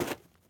SnowSteps_03.wav